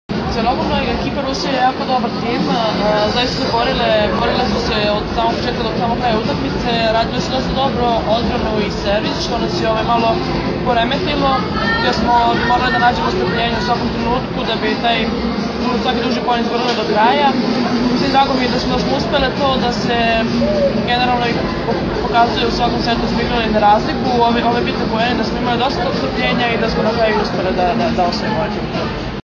IZJAVA MILENE RAŠIĆ